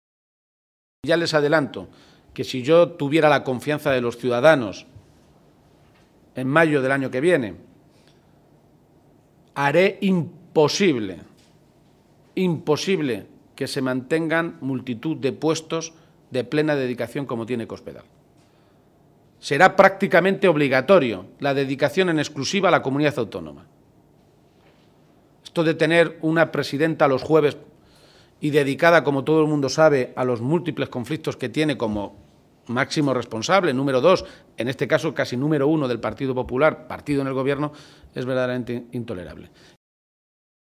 El líder de los socialistas castellano-manchegos ha realizado estas declaraciones en un desayuno informativo en Ciudad Real, donde ha estado acompañado por el secretario provincial del PSOE en esta provincia, José Manuel Caballero, por la secretaria de Organización, Blanca Fernández, y por la portavoz regional, Cristina Maestre.